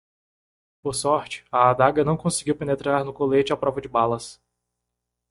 Read more (feminine) dagger (a stabbing weapon) (feminine) dagger (the character †) Frequency C2 Pronounced as (IPA) /aˈda.ɡɐ/ Etymology First attested in the 16th century.